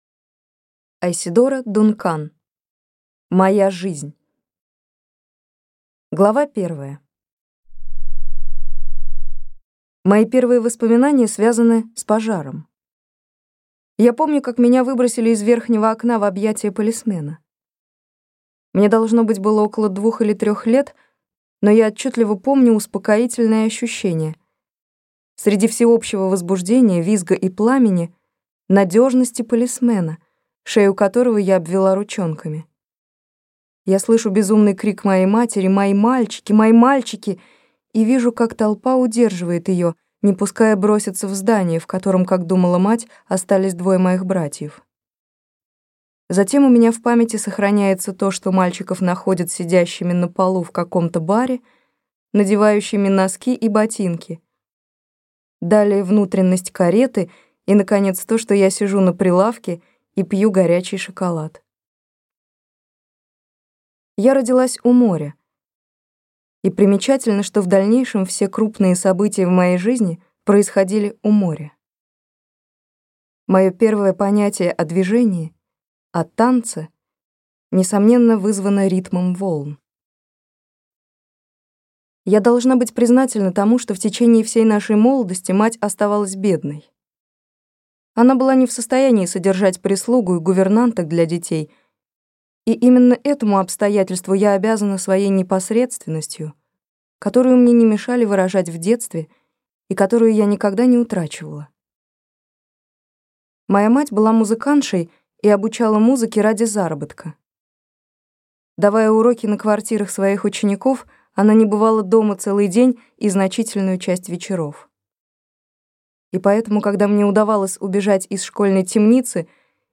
Аудиокнига Моя жизнь | Библиотека аудиокниг